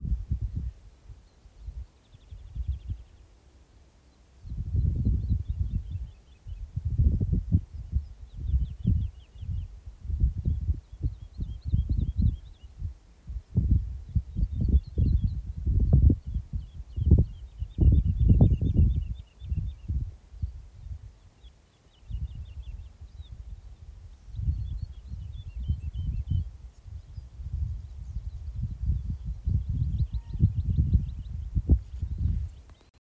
Sila cīrulis, Lullula arborea
StatussDzied ligzdošanai piemērotā biotopā (D)